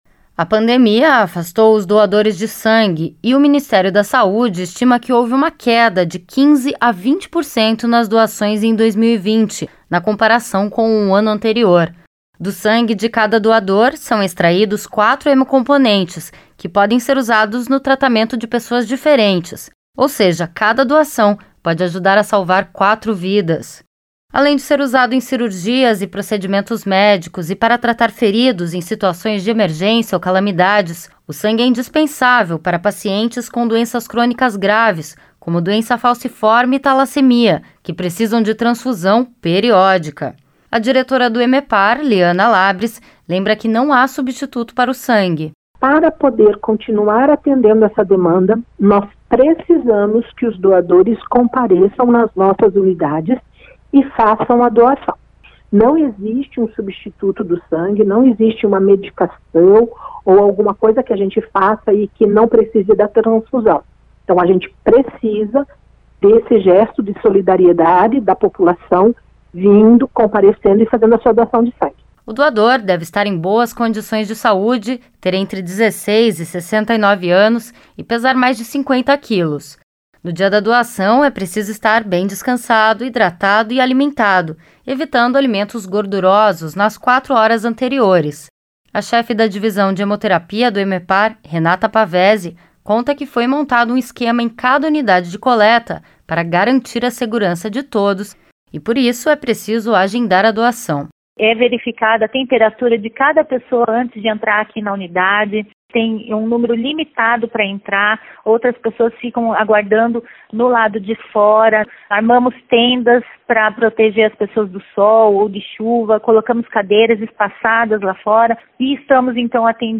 E começa a série de reportagens Cada Gota Faz a Diferença, sobre a importância e a necessidade da doação de sangue no Paraná.